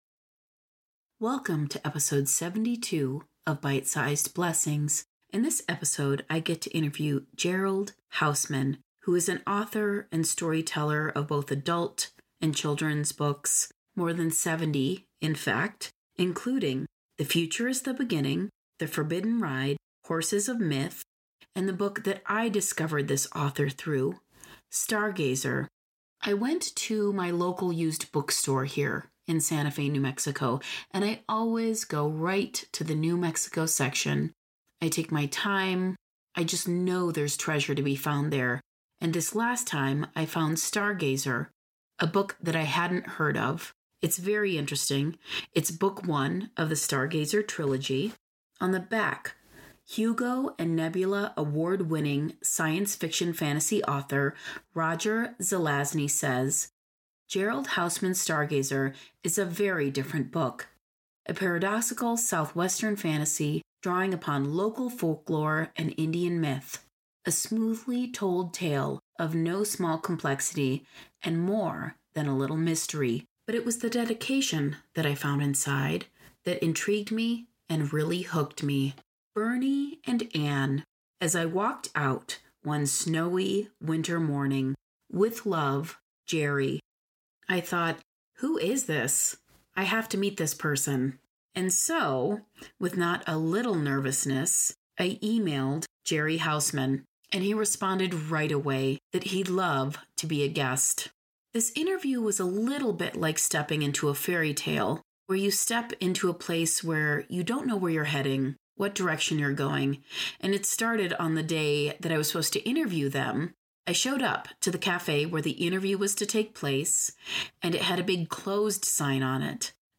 at least that what it felt like during our Zoom interview